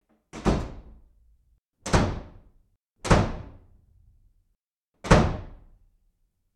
Звуки хлопанья дверью
Звук быстро хлопающей двери 4 раза